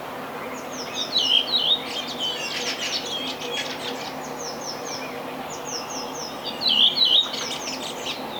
mikä ääntelee taustalla,
Äänite: punakylkirastas laulaa ja
laulussaan säestää pääsäettään visertelyosuudella